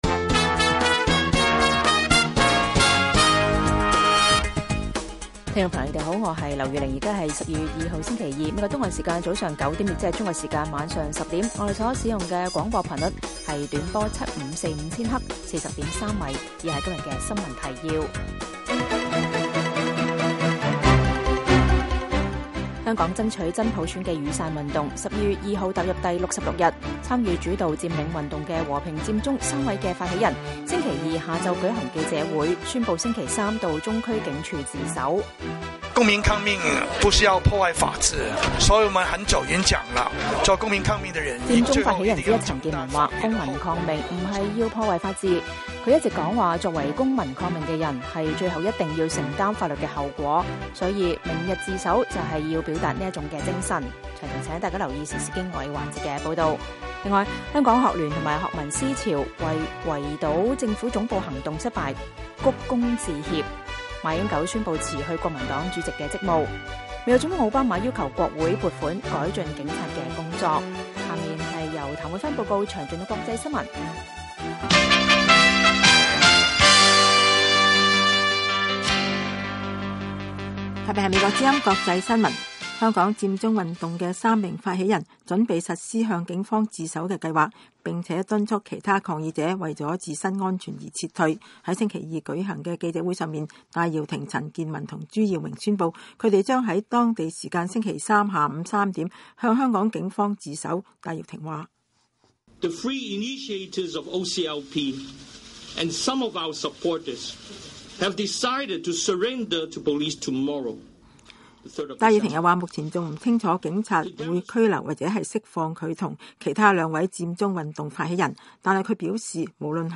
粵語新聞 晚上10-11點
每晚 10點至11點 (1300-1400 UTC)粵語廣播，內容包括簡要新聞、記者報導和簡短專題。